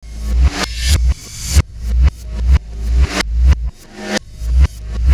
Black Hole Beat 06.wav